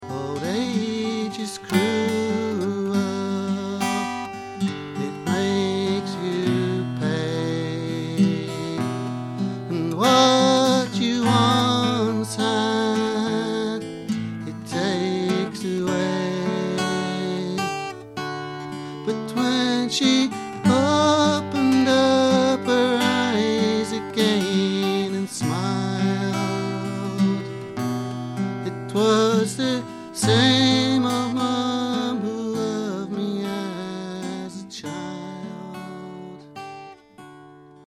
He began with a very Scottish song from the album which he followed with a self-penned song written for his mother simply called, 'She Was My Mother'.
It's all at Ashington Folk Club!